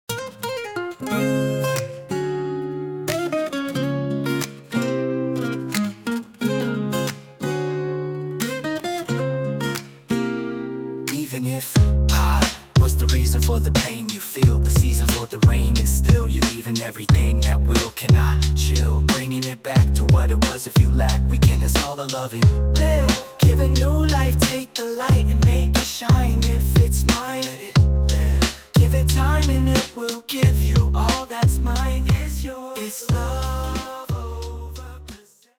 An incredible Funk song, creative and inspiring.